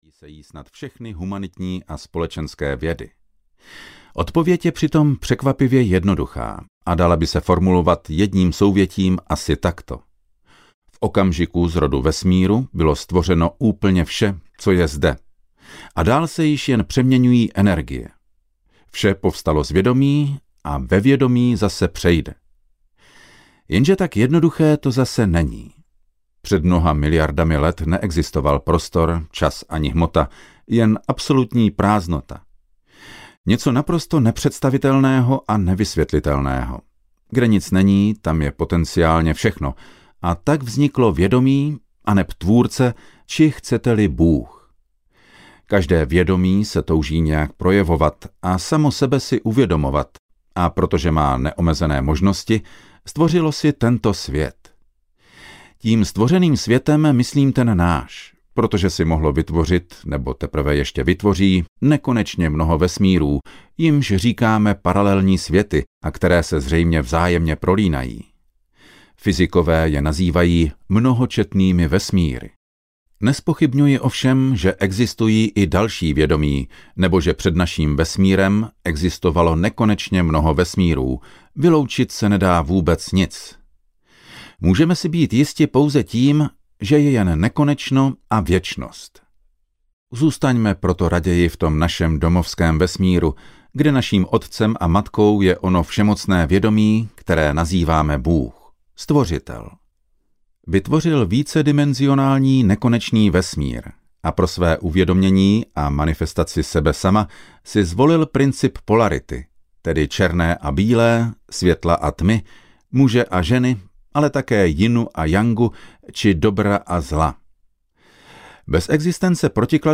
Indigová realita audiokniha
Ukázka z knihy